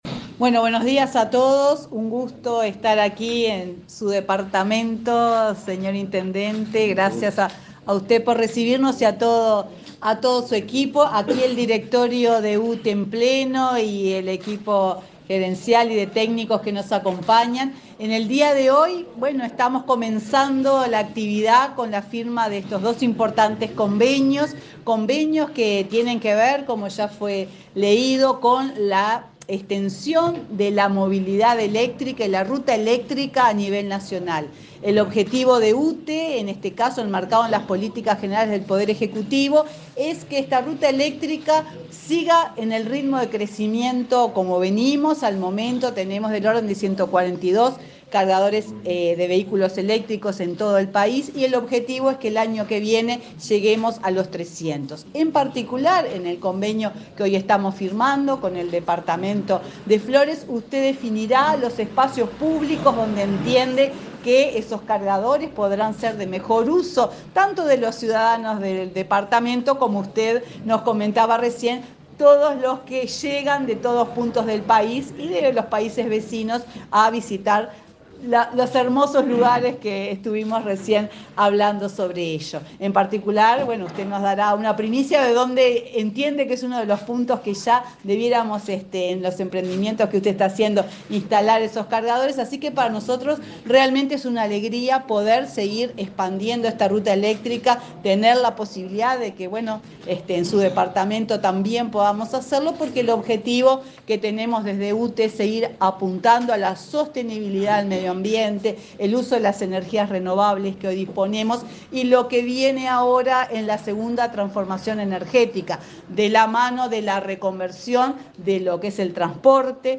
Palabras de autoridades en firma de convenio entre UTE e Intendencia de Flores